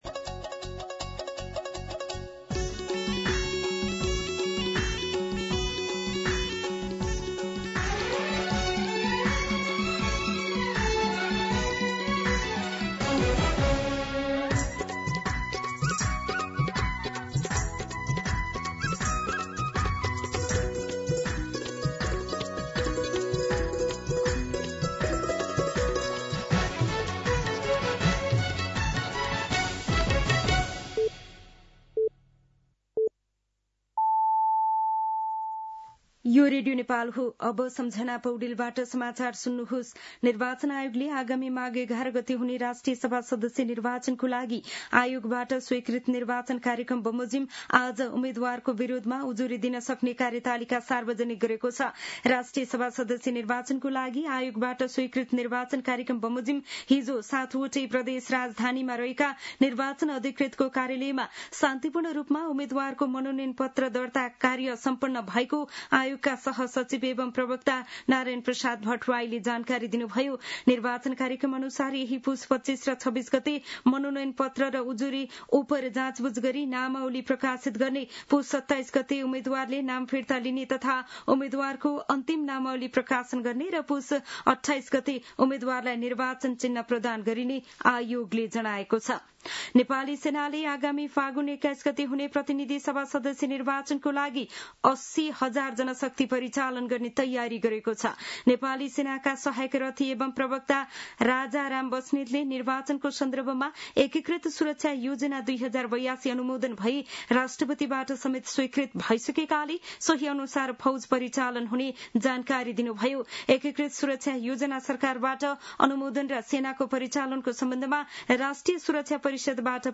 दिउँसो ४ बजेको नेपाली समाचार : २४ पुष , २०८२